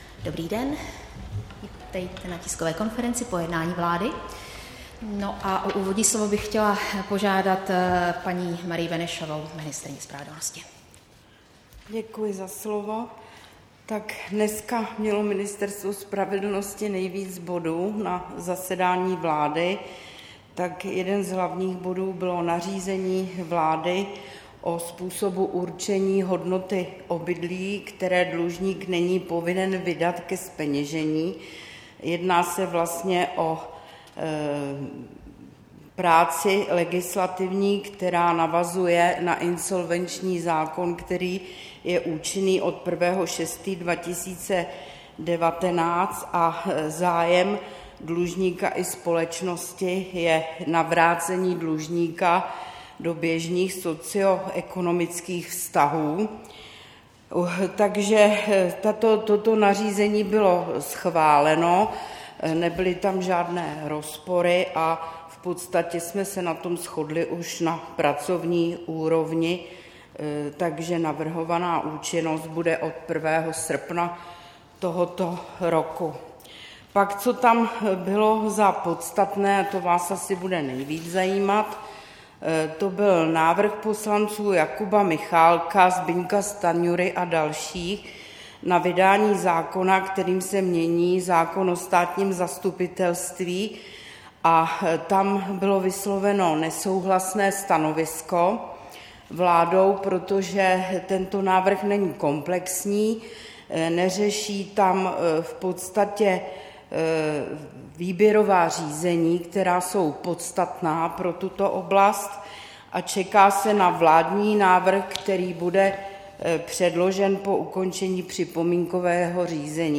Tisková konference po jednání vlády, 22. července 2019